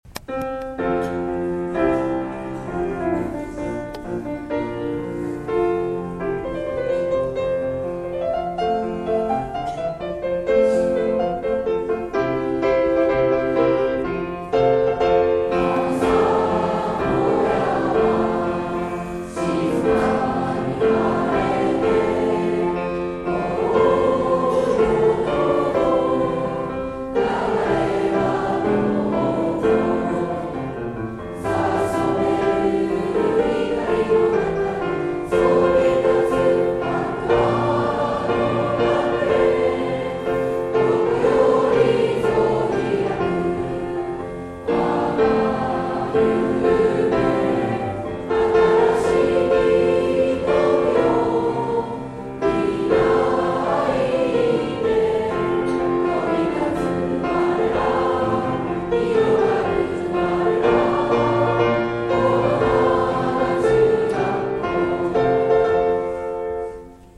大阪市立此花中学校の平成27年度修了式が行われました。 １・２年生の柔らかな校歌の歌声で早春の修了式はスタート。